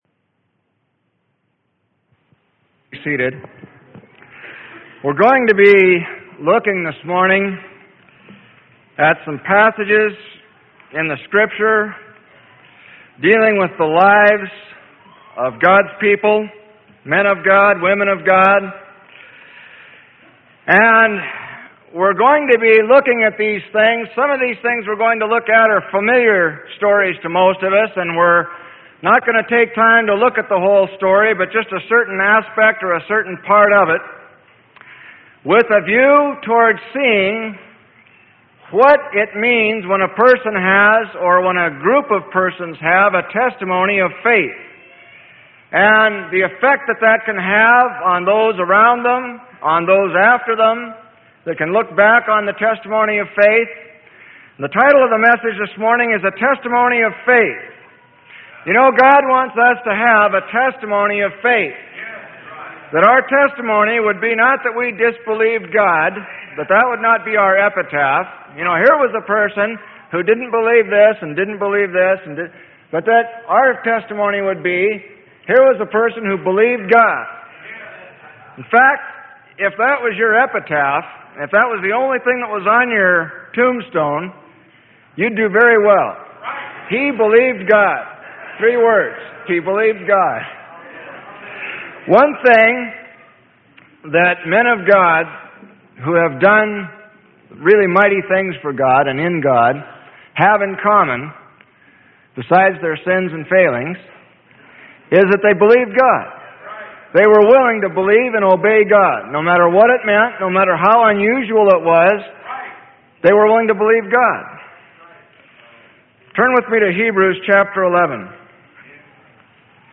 Sermon: A Testimony of Faith - Freely Given Online Library